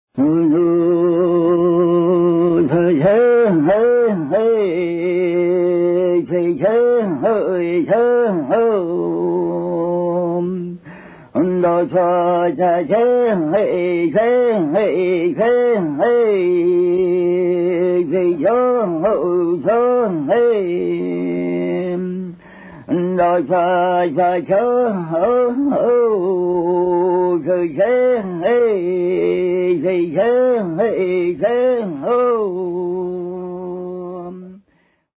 Shaman Initiation Song/Chant - :47